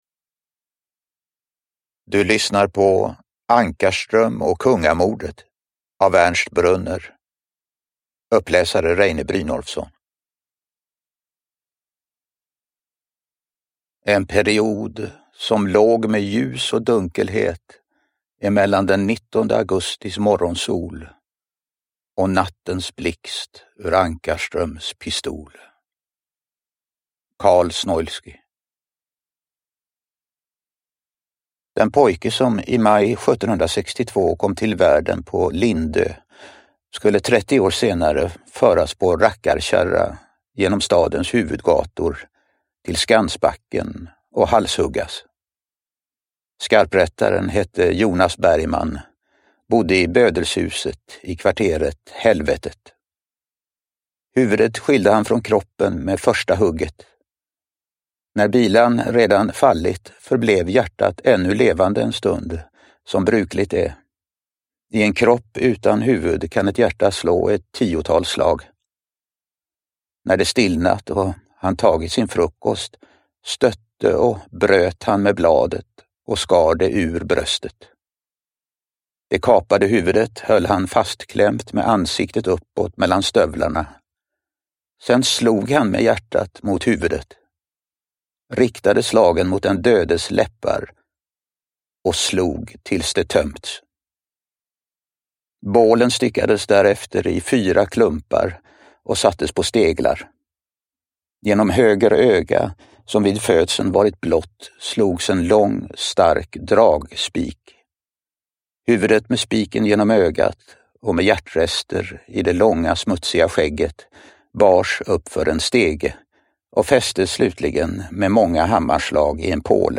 Uppläsare: Reine Brynolfsson
Ljudbok